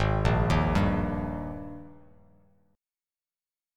AbM7sus2 chord